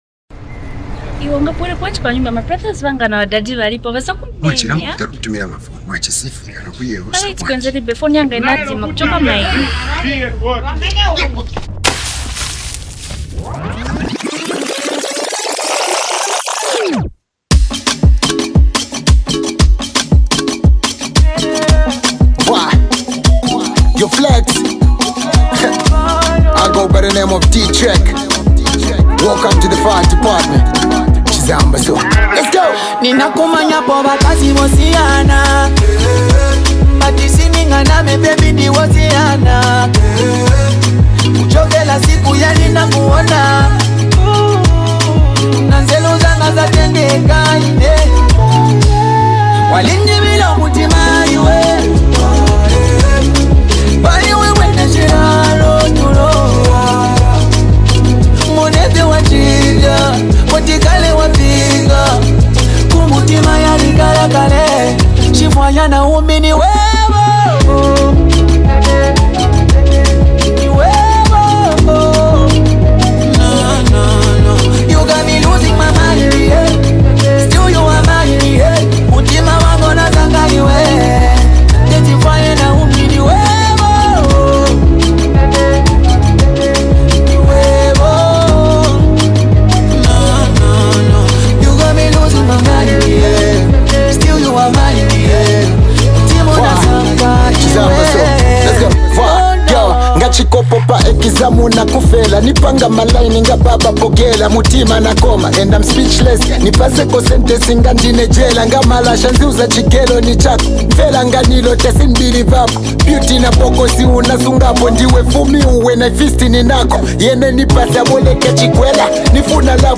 one of Zambia’s most celebrated hip-hop artists
melodic vocals